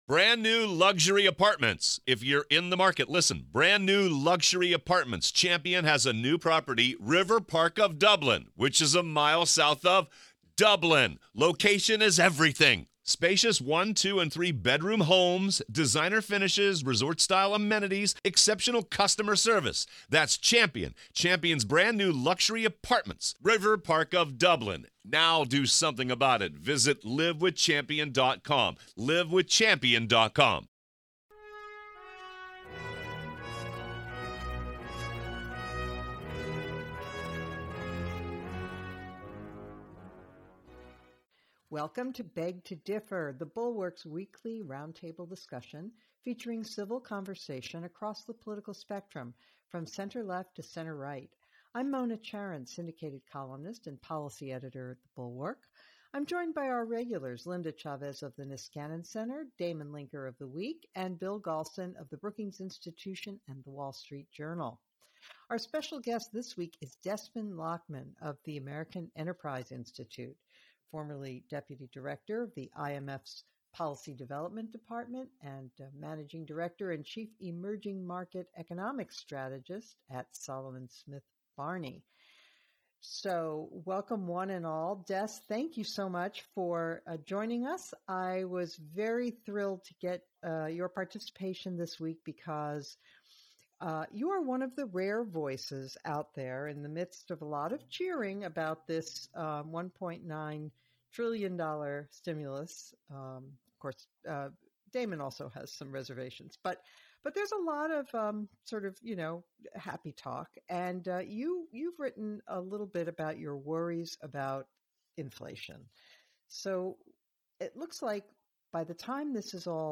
The panel then considers the border crisis and the GOP's outreach to the working class.